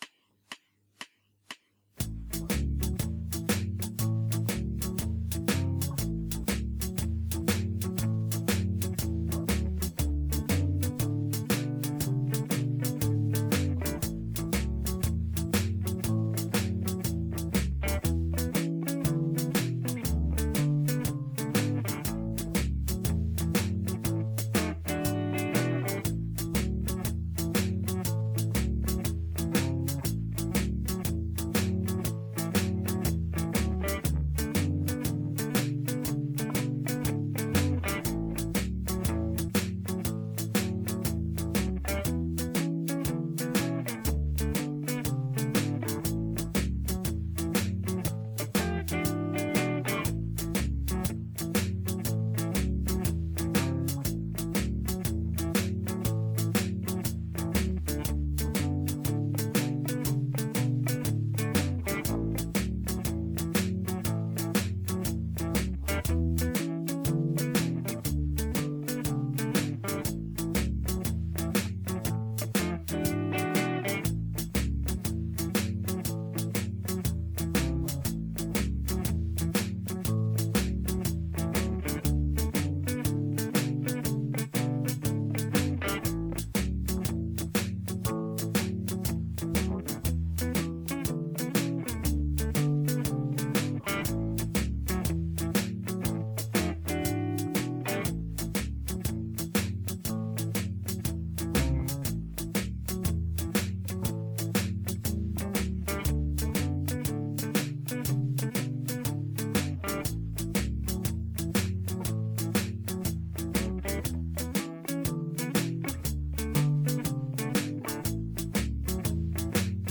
13Podkład (Blues w A):